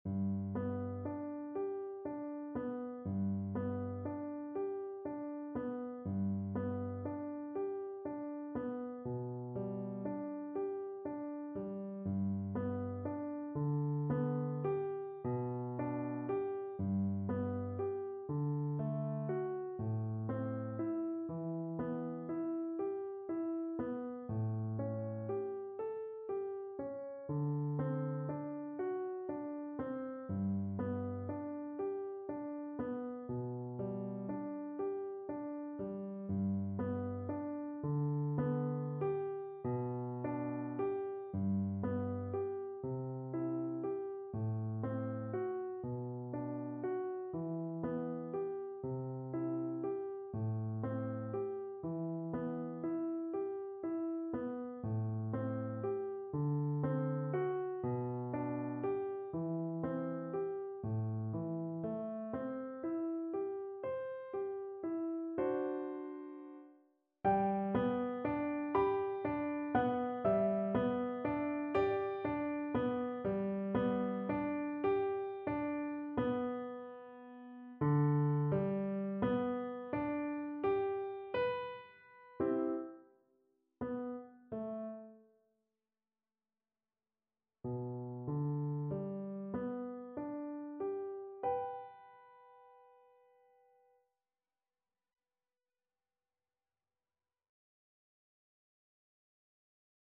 Puccini: O mio babbino caro… (na skrzypce i fortepian)
Symulacja akompaniamentu